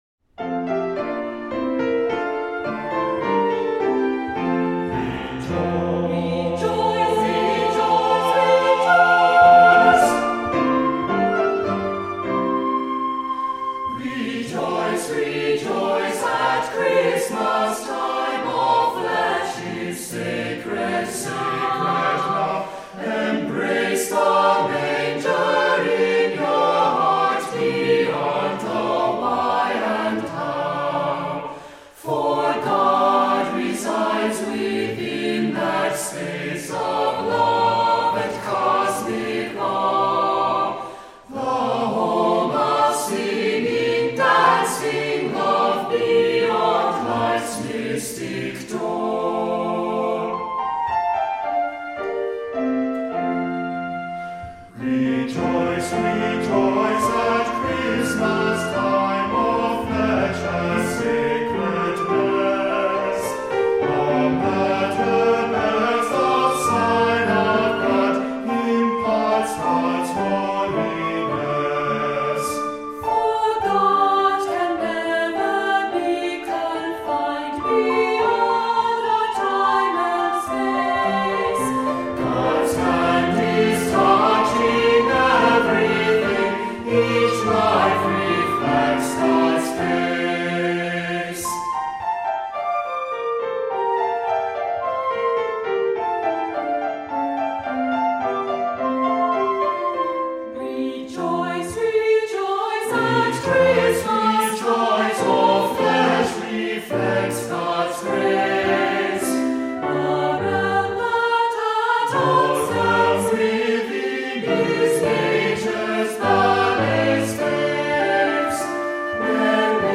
Voicing: SATB,Descant,Assembly